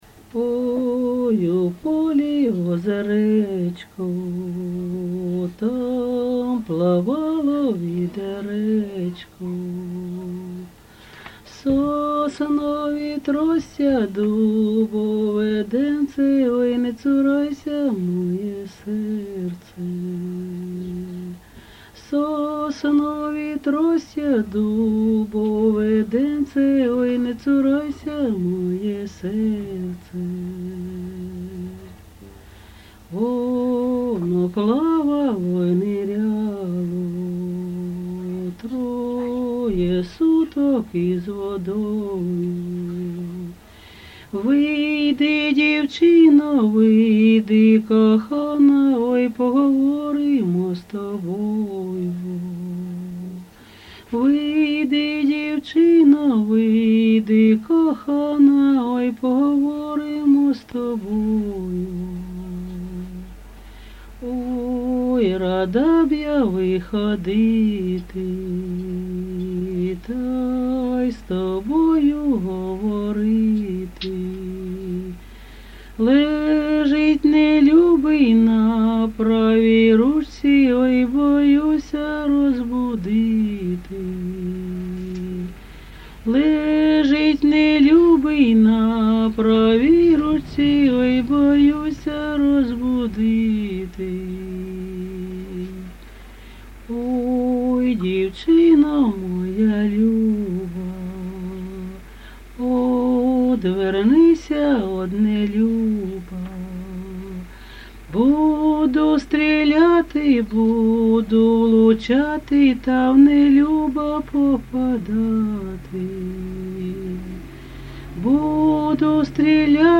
ЖанрПісні з особистого та родинного життя
Місце записус. Серебрянка, Артемівський (Бахмутський) район, Донецька обл., Україна, Слобожанщина